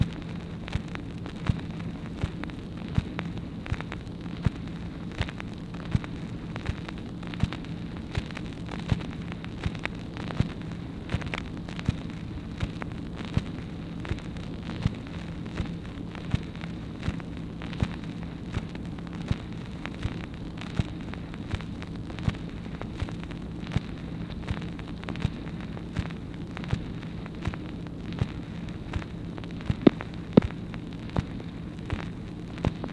MACHINE NOISE, April 9, 1964
Secret White House Tapes | Lyndon B. Johnson Presidency